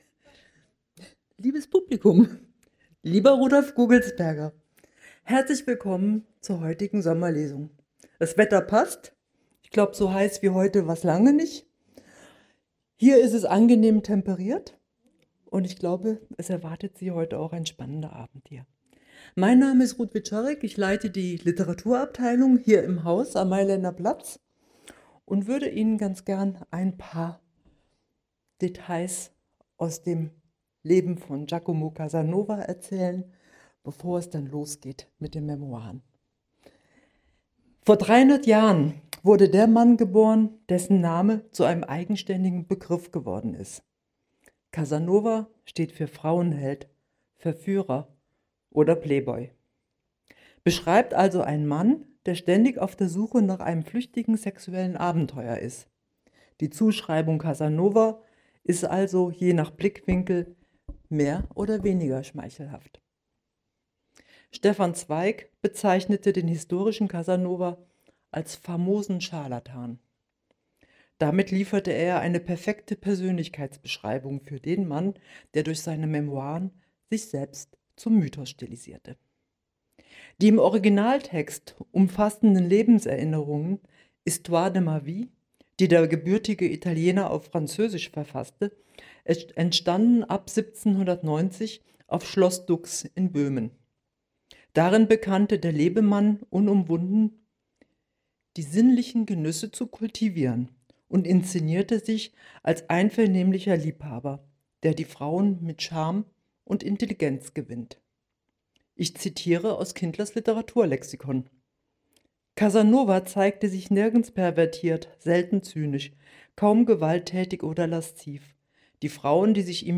Audio-Mitschnitt der Veranstaltung (Dauer: 1:15 h): MP3 in neuem Fenster öffnen | Download MP3 (62 MB)
Vorlesen